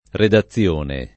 [ reda ZZL1 ne ]